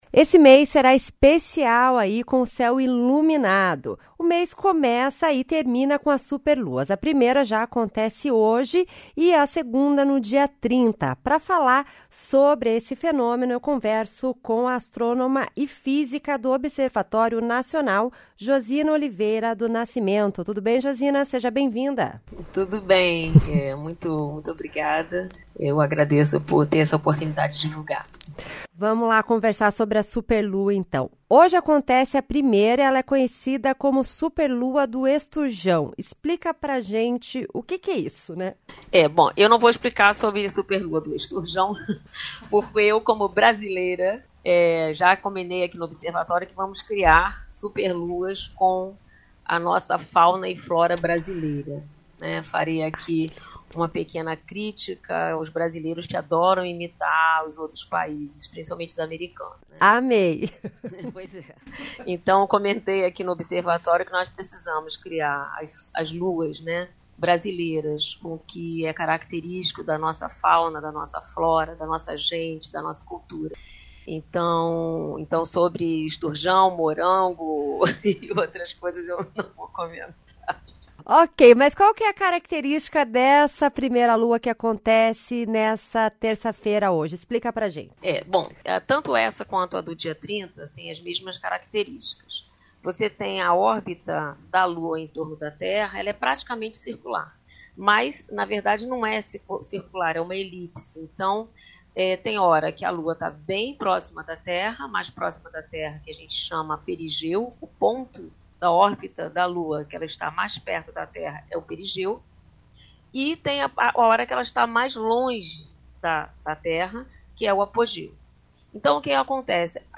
conversa com a astrônoma e física